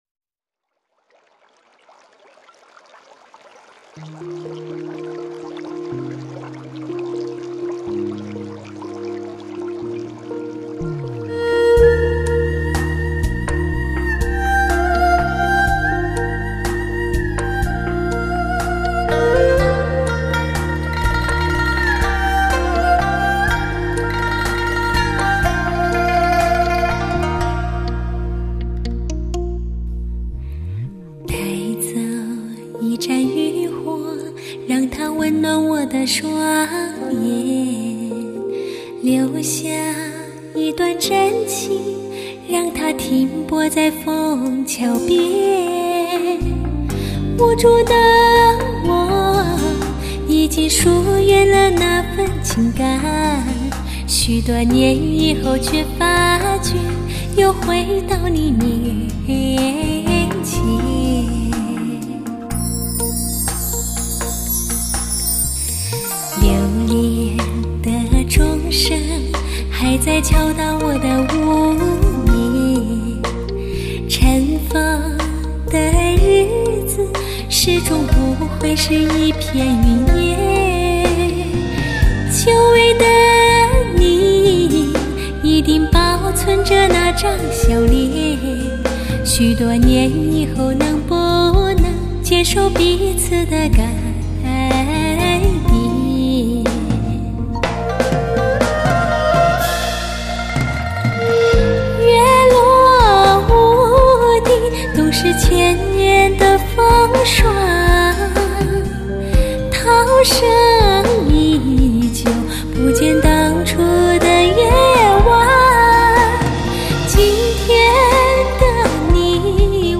聆听的专业典范，全新华语乐坛极品女声。
金牌女声之珍贵演绎，靡靡柔腻之音令人动容、令人情动！